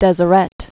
(dĕzə-rĕt)